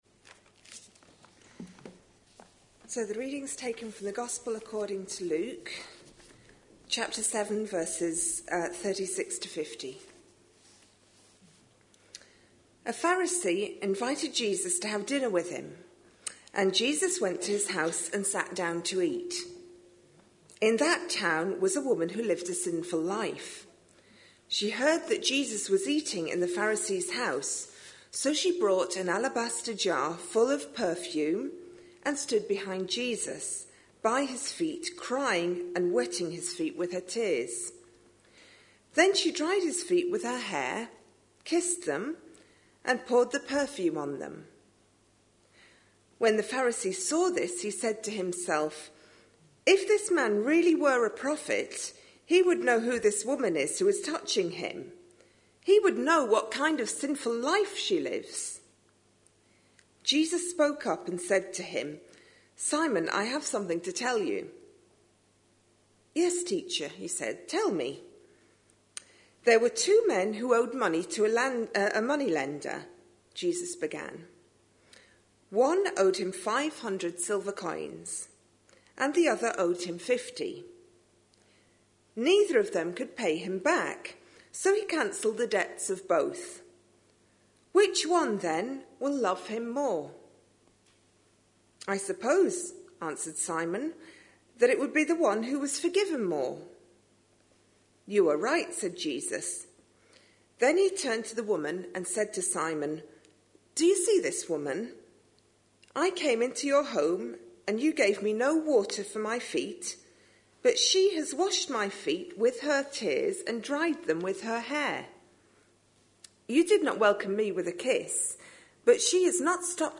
A sermon preached on 19th January, 2014, as part of our Stories with Intent series.